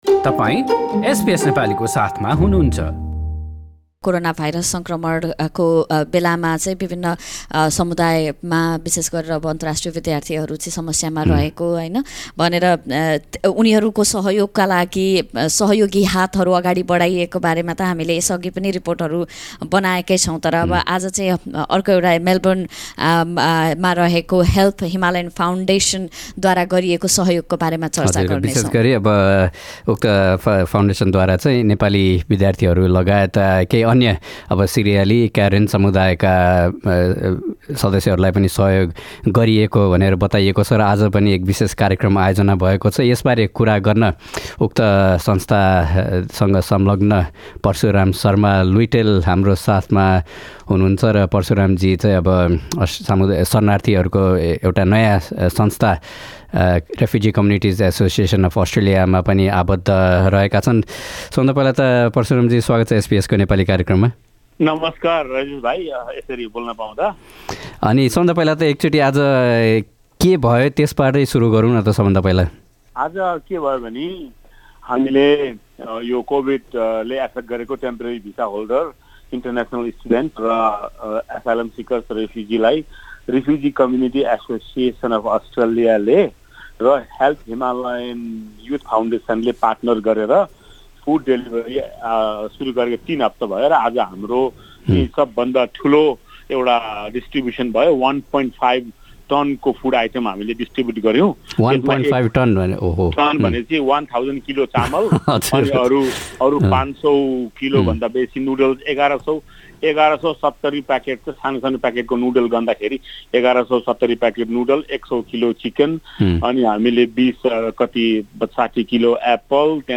पुरा कुराकानी